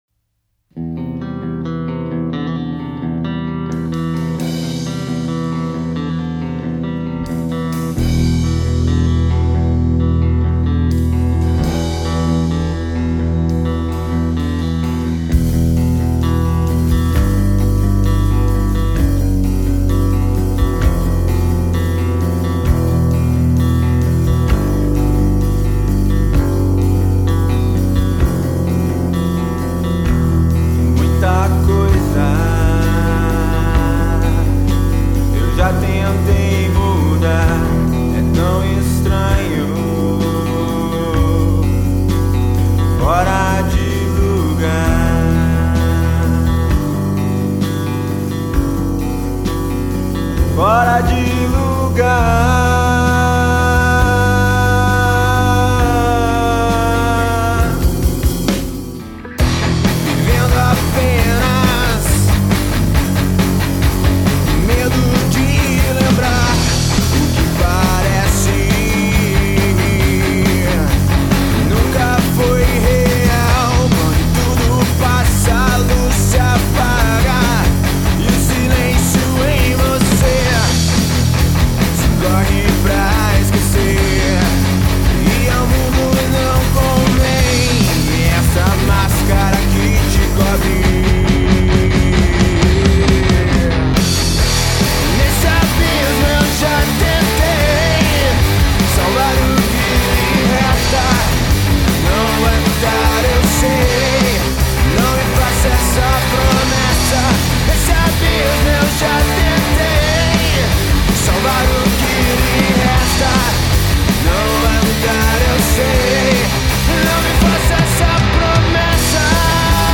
EstiloHard Rock